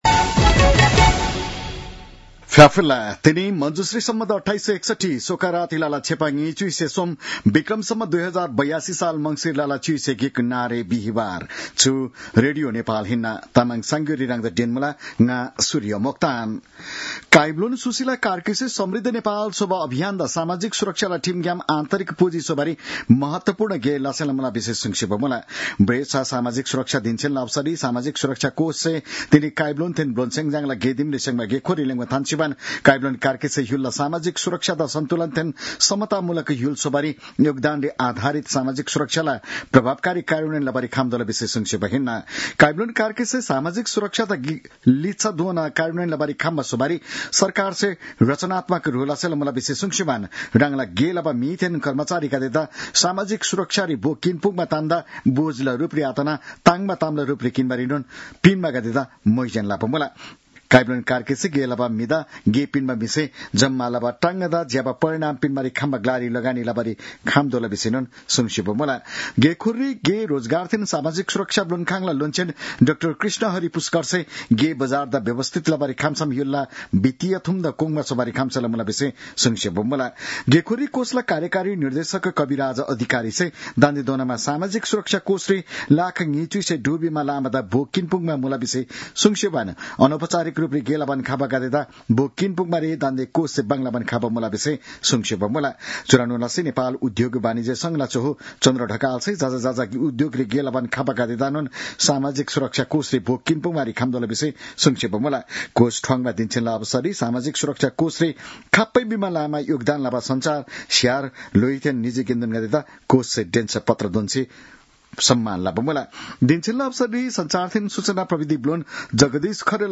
तामाङ भाषाको समाचार : ११ मंसिर , २०८२
Tamang-news-8-11.mp3